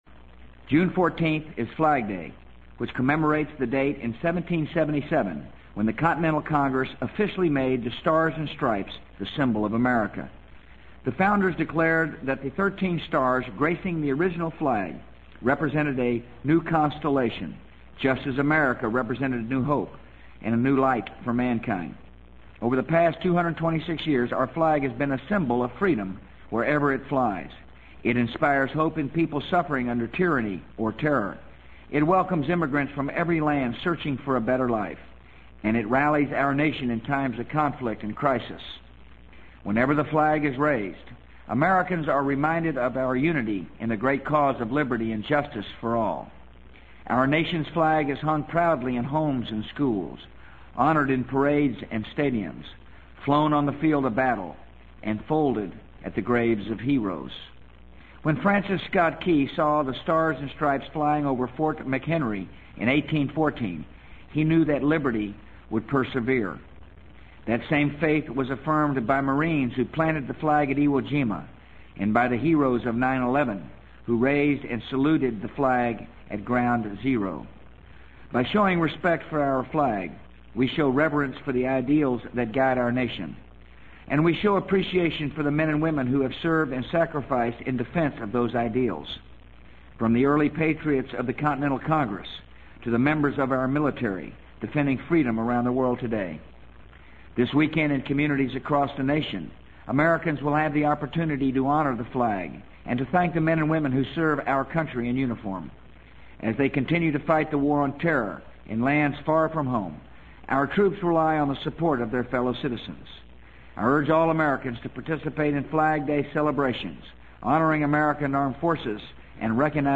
【美国总统George W. Bush电台演讲】2003-06-14 听力文件下载—在线英语听力室